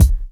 kick 16.wav